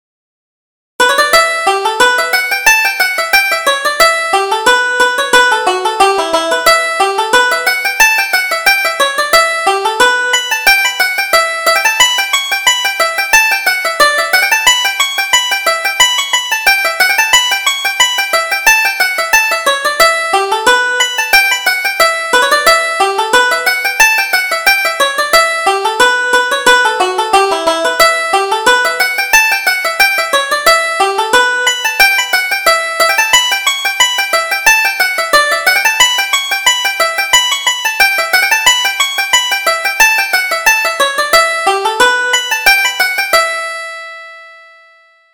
Reel: The Girl with the Laughing Eyes